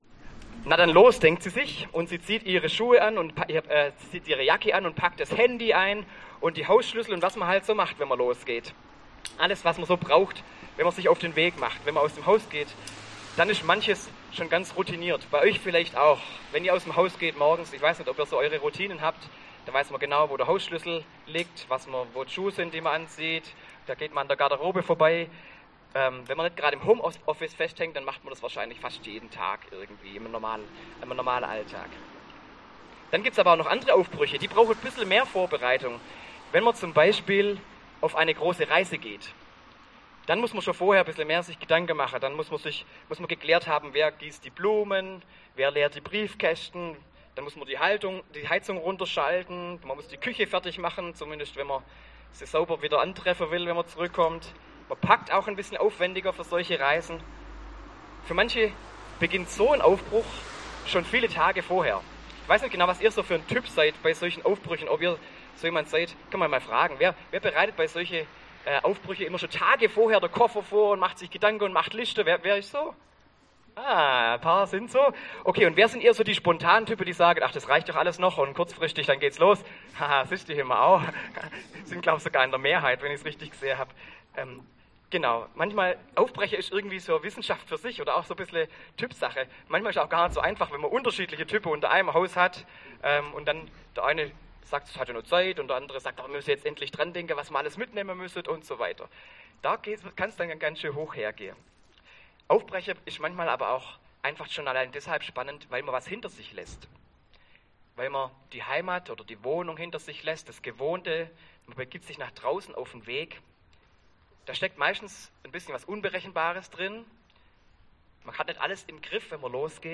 Predigt in drei Teilen an drei Stationen im Gottesdienst unterwegs am 2. Weihnachtsfeiertag. (Aufnahme teilweise mit Hintergrundgeräuschen.)